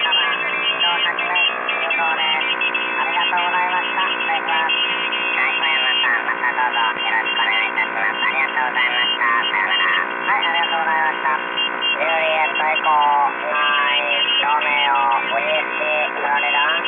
いずれもSSBで復調したWAVファイルです。
ガラガラガラガラといううがいをするようなノイズで、これは間欠的に出たり出なかったりします。